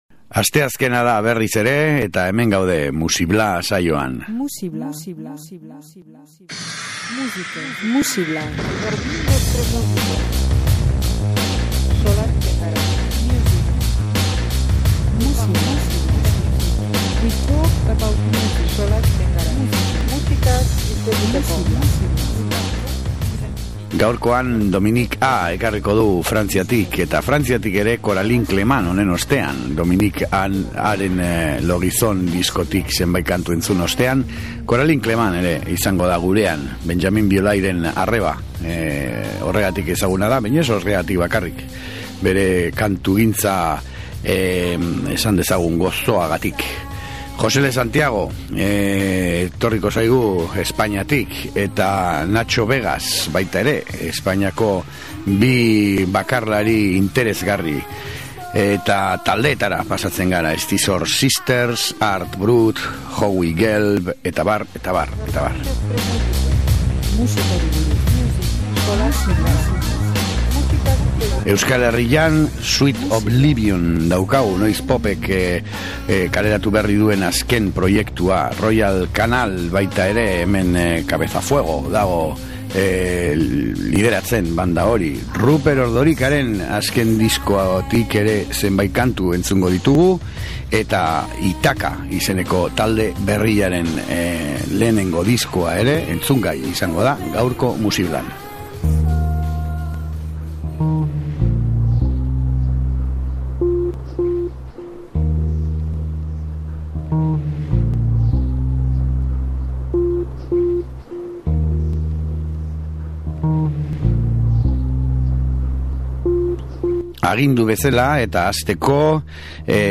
bere gozotasun hunkigarriarekin.